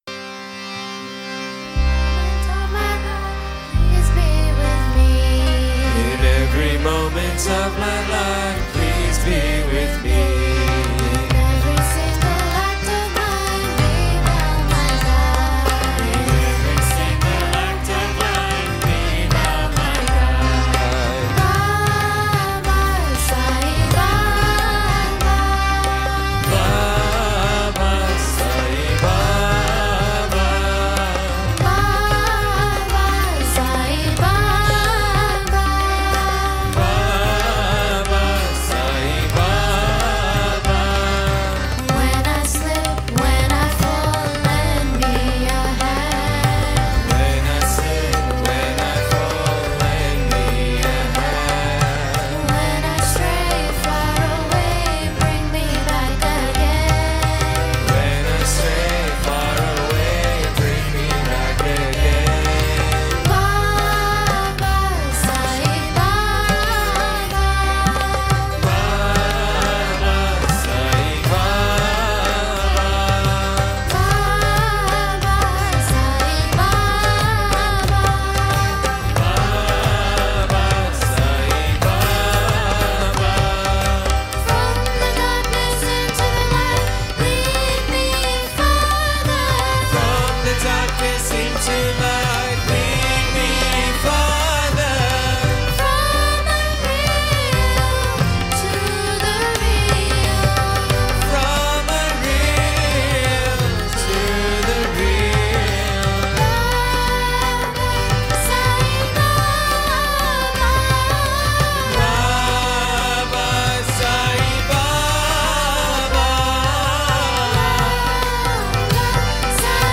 1. Devotional Songs
Harmonic Minor 8 Beat  Men - 2 Pancham  Women - 6 Pancham
Harmonic Minor
8 Beat / Keherwa / Adi
Medium Fast
Lowest Note: n2 / B (lower octave)
Highest Note: G1 / E♭ (higher octave)